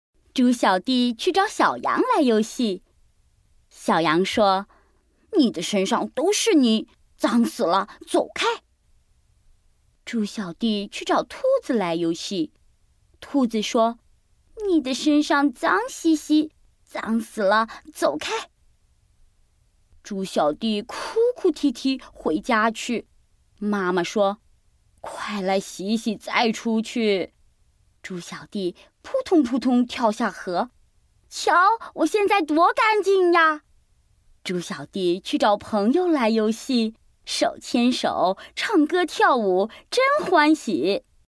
MN XL AB 01 Audiobooks Female Mandarin